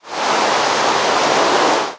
rain1.ogg